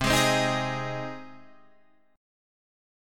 Cm#5 chord